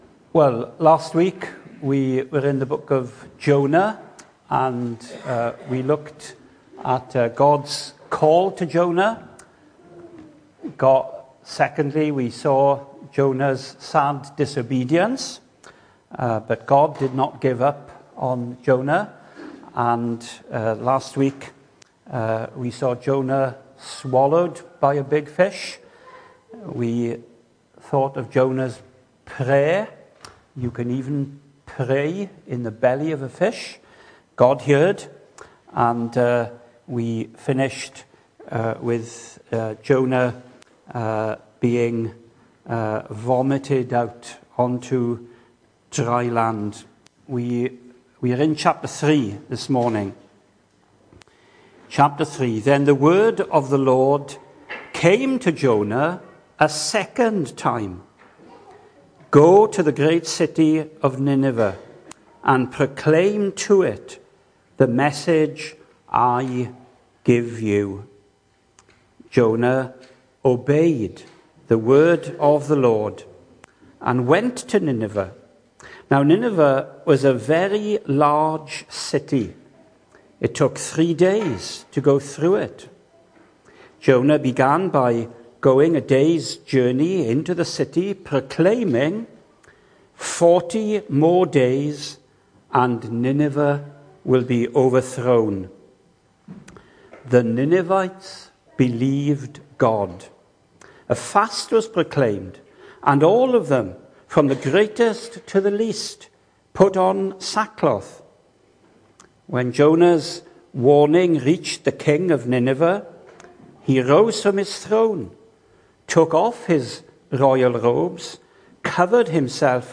Hello and welcome to Bethel Evangelical Church in Gorseinon and thank you for checking out this weeks sermon recordings.
The 11th of January saw us host our Sunday morning service from the church building, with a livestream available via Facebook.